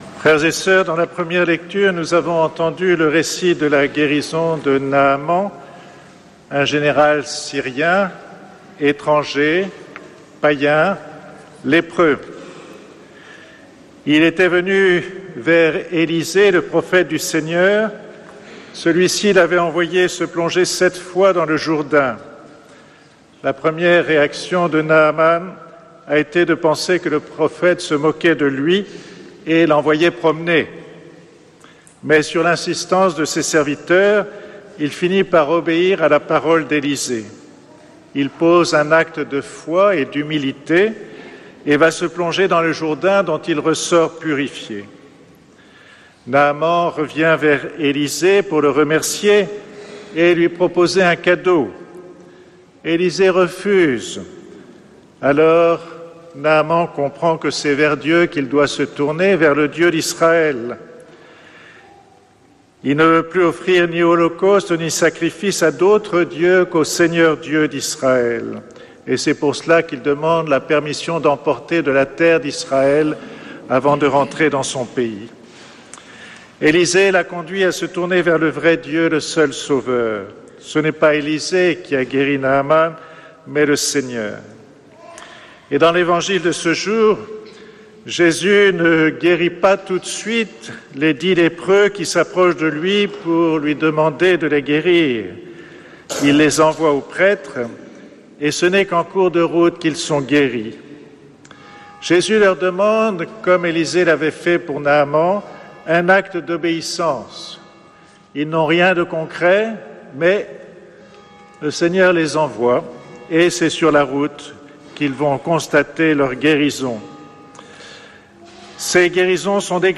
Homélie de Monseigneur Guy de Kérimel, archevévêque de Toulouse
Lors de la messe d’installation